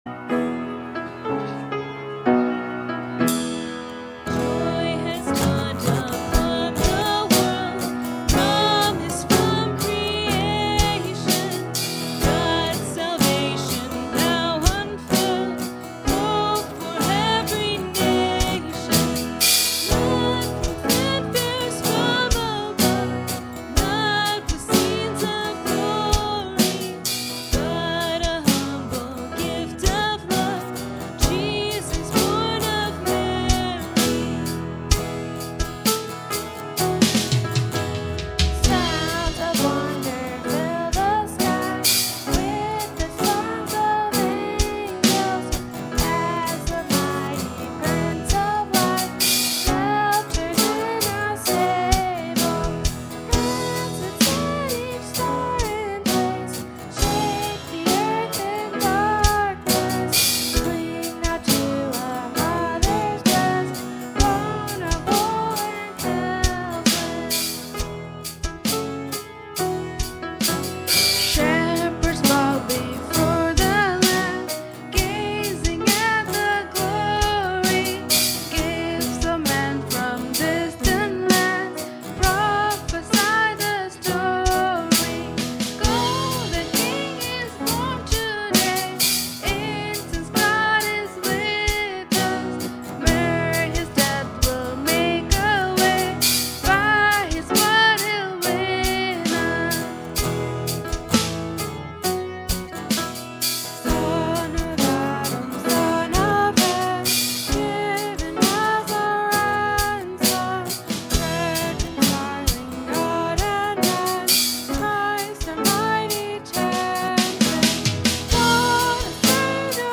The children’s program is the whole service and is a 1 hour and 8 minute recording.